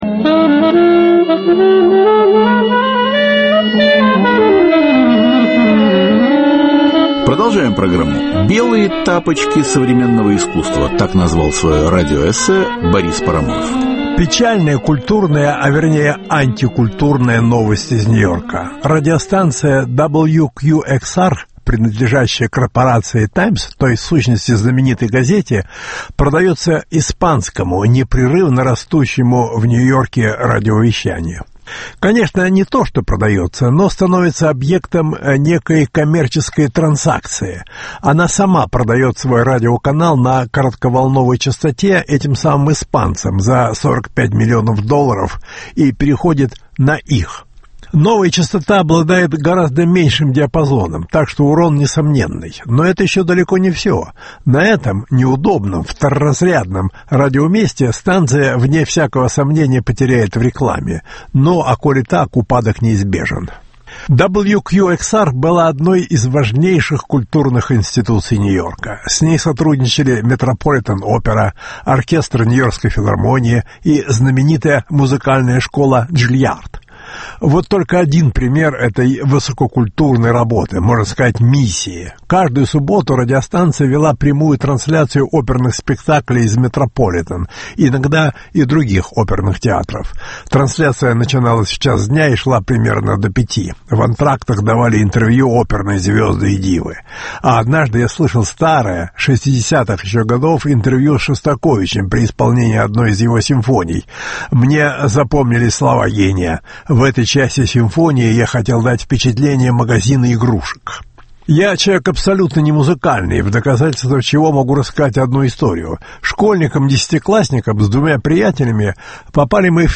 Белые тапочки современного искусства – радиоэссе Бориса Парамонова.